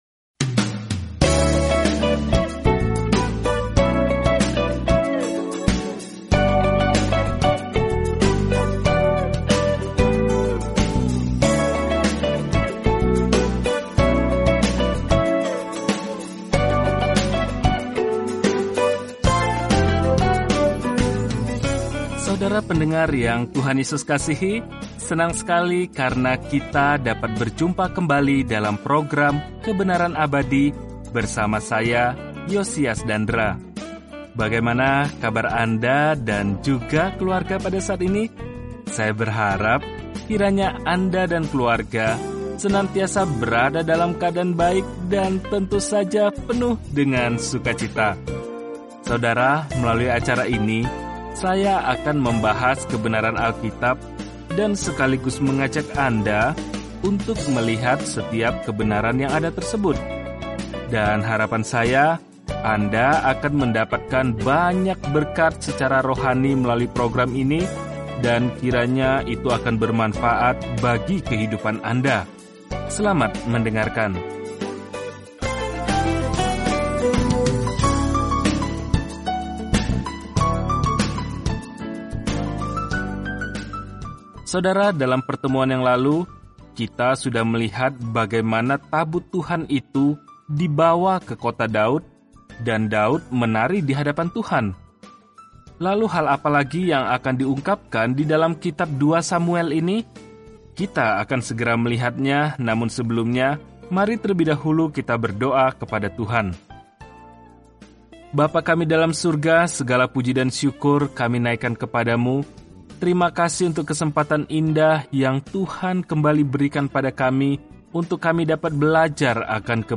Telusuri 2 Samuel setiap hari sambil mendengarkan pelajaran audio dan membaca ayat-ayat tertentu dari firman Tuhan.